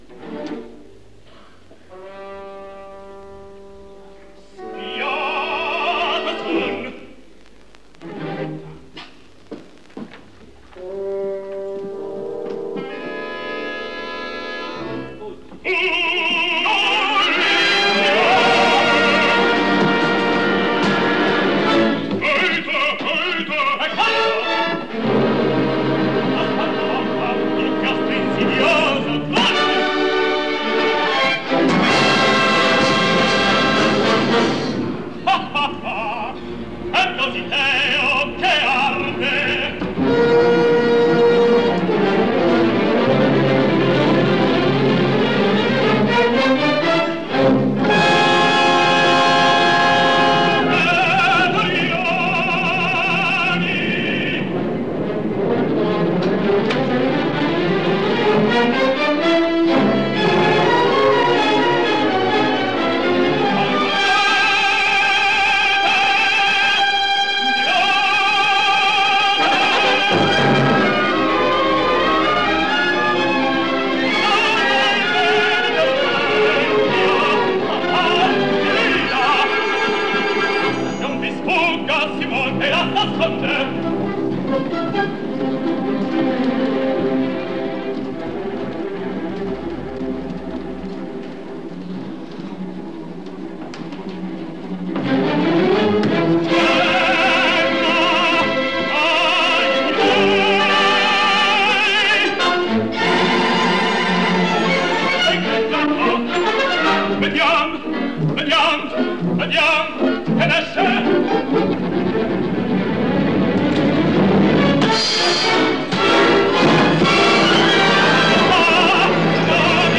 Luogo esecuzioneNapoli